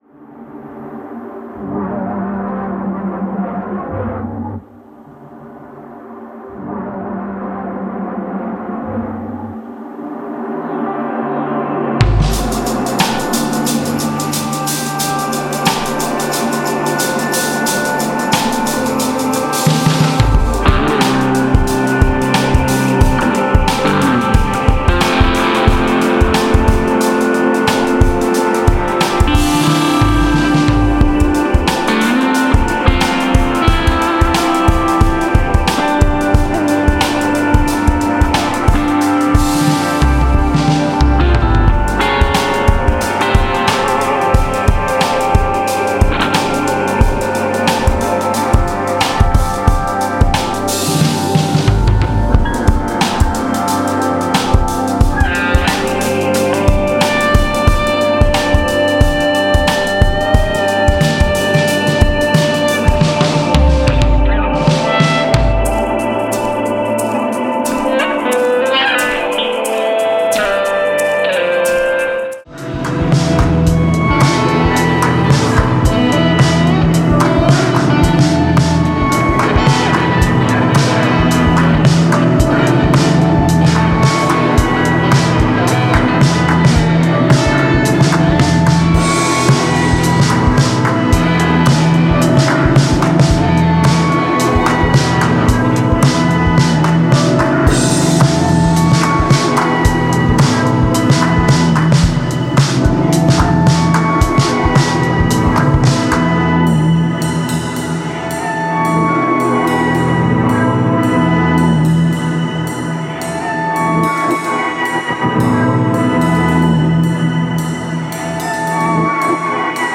AMBIENT · INDUSTRIAL · EXPERIMENTAL